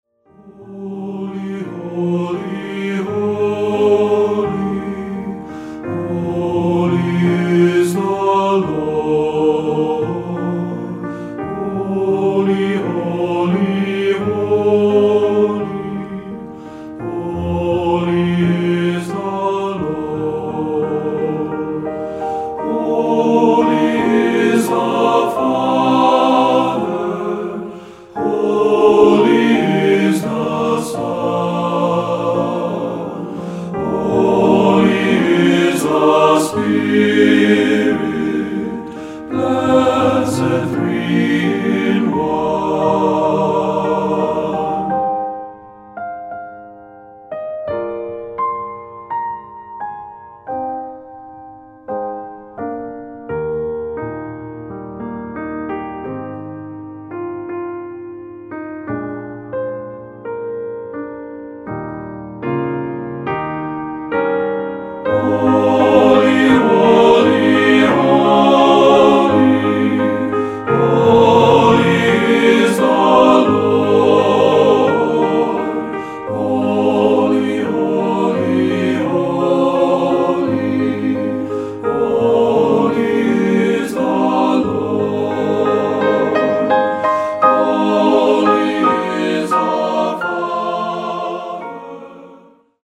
Choeur TTB